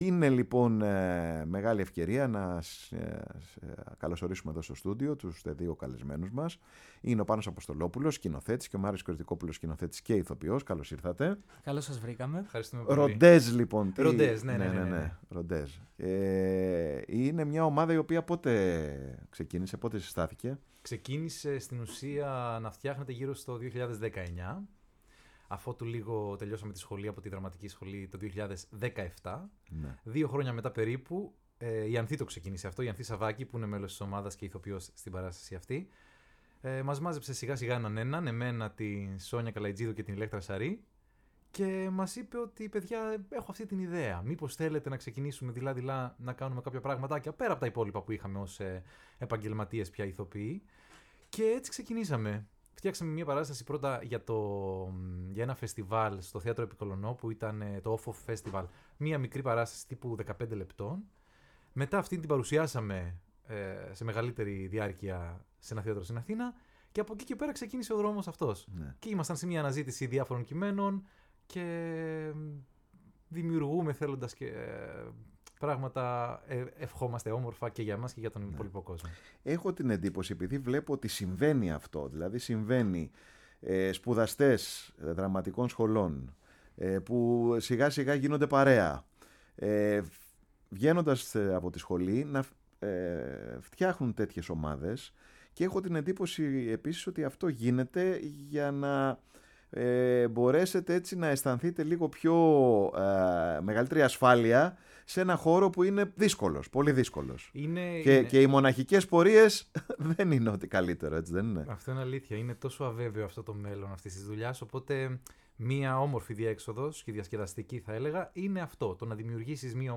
Συνεντεύξεις